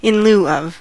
in-lieu-of: Wikimedia Commons US English Pronunciations
En-us-in-lieu-of.WAV